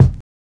BASS POP.wav